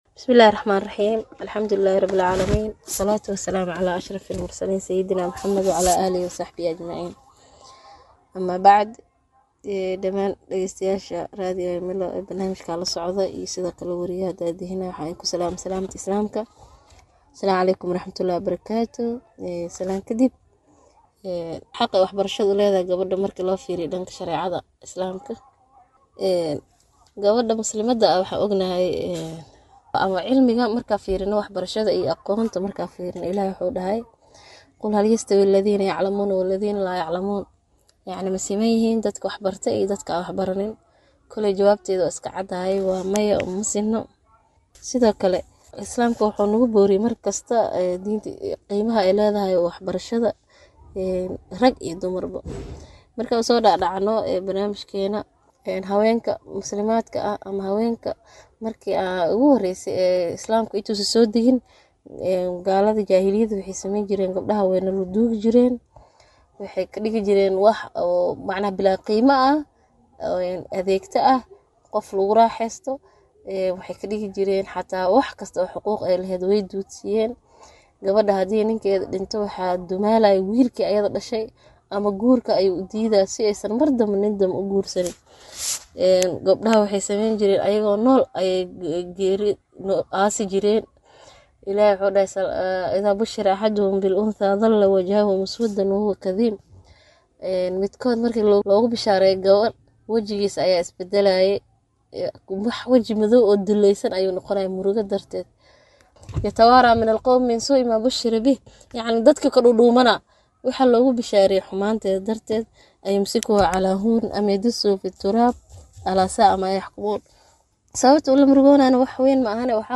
Wareysi: Muhiimadda waxbarashada u leedahay Gabdhaha